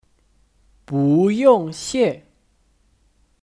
不用谢 (Búyòng xiè 不用谢)